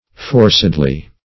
forcedly - definition of forcedly - synonyms, pronunciation, spelling from Free Dictionary
-- For"ced*ly, adv.